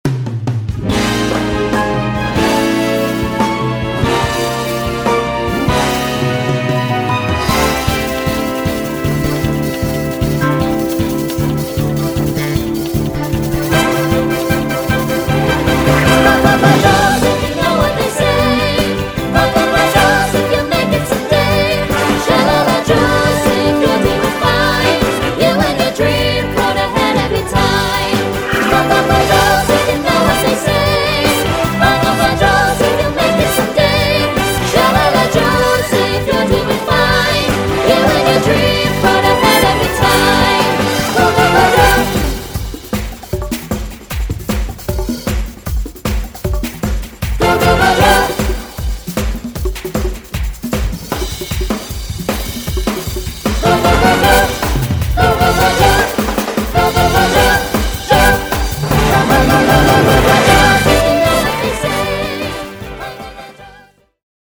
Fully produced rehearsal track with studio backing vocals